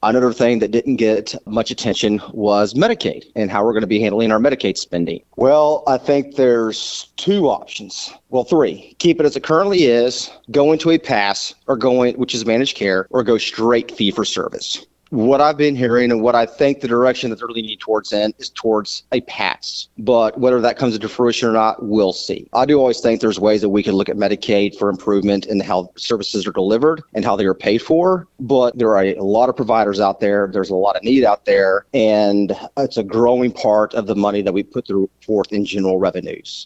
With the start of the 95th legislative session in Arkansas, Scott Flippo, State Senator representing District 23, spoke with KTLO, Classic Hits and The Boot News to share his experiences of the past few days.